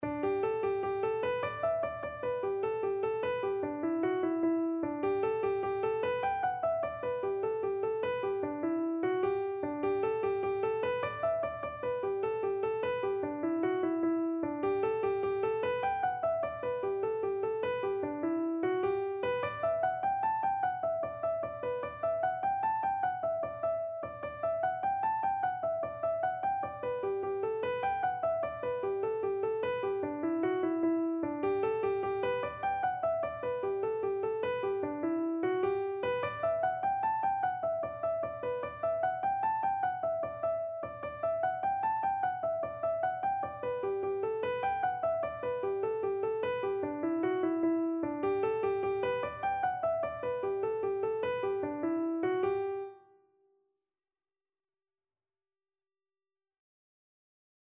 Free Sheet music for Keyboard (Melody and Chords)
G major (Sounding Pitch) (View more G major Music for Keyboard )
6/8 (View more 6/8 Music)
Keyboard  (View more Intermediate Keyboard Music)
Traditional (View more Traditional Keyboard Music)
Irish